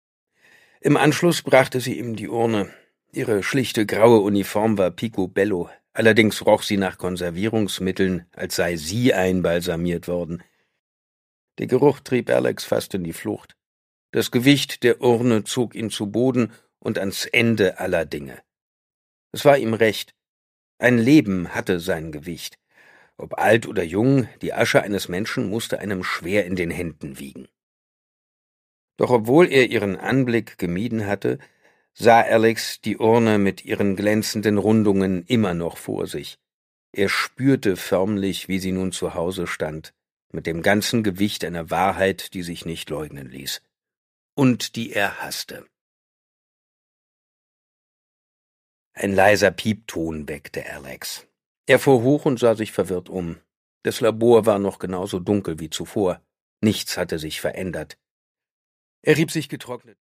Produkttyp: Hörbuch-Download
Gelesen von: Simon Jäger
Simon Jäger macht mit seiner markanten, eindringlichen Stimme diese Space Opera zu einem spannenden Hörerlebnis.